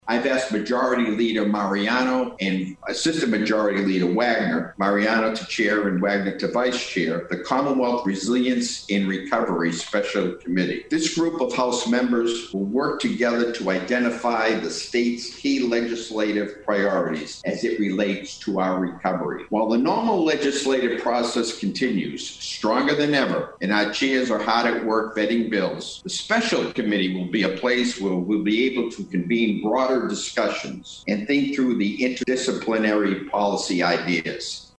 House Speaker Robert DeLeo also spoke at the event where he announced the formation of a new Committee on Beacon Hill.